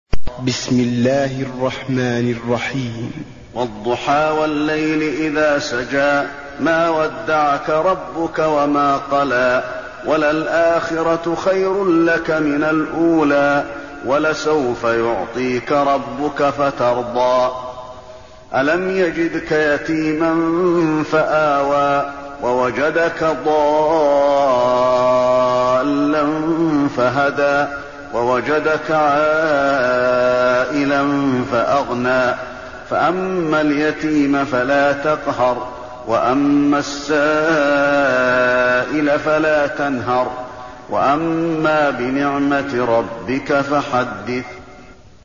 المكان: المسجد النبوي الضحى